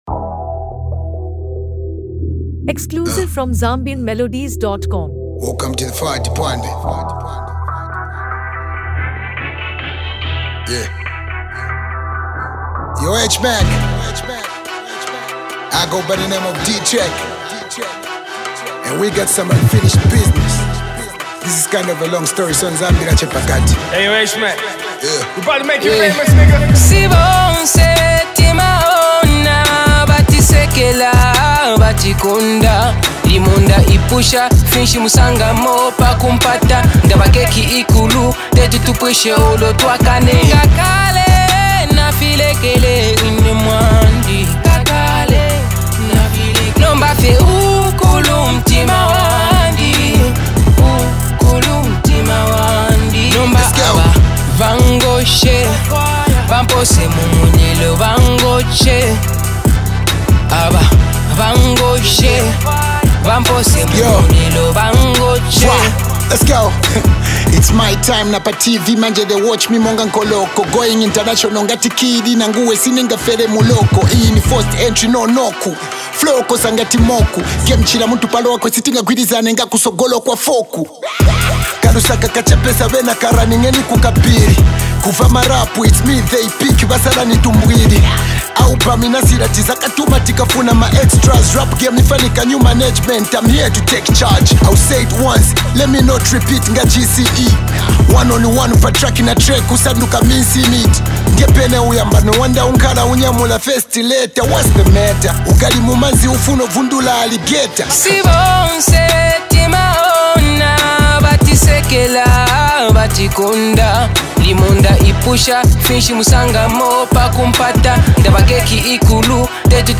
Zambian Hip-Hop
a hard-hitting rap anthem
Genre:Hip Hop